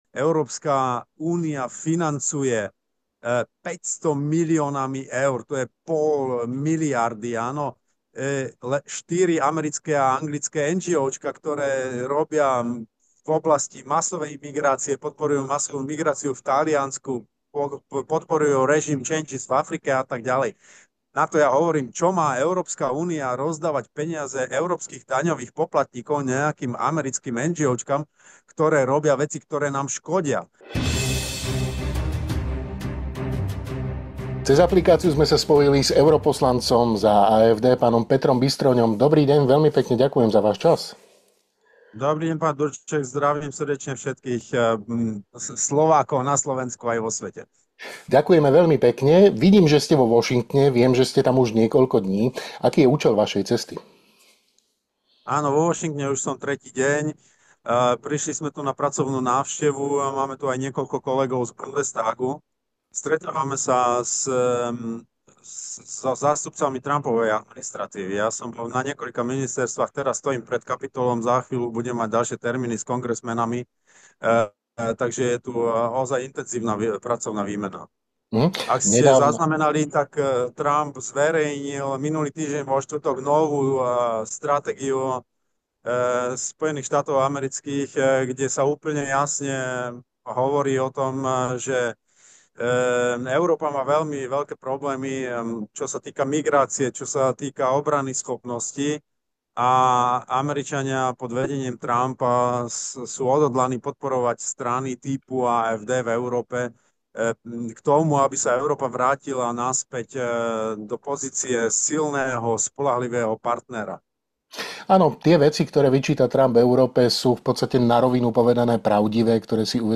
O spolupráci AfD s Trumpovou administratívou, ale aj zmene postoja Merzovej vlády k zavedeniu chat-control, napätí vo vnútri aktuálnej vládnej koalície v Nemecku a jej strachu z prípadných ďalších predčasných volieb, no aj o financovaní mimoeurópskych mimovládok podporujúcich masovú migráciu z peňazí európskych daňových poplatníkov, budete počuť vo videorozhovore s bavorským šéfom AfD, Petrom Bystroňom.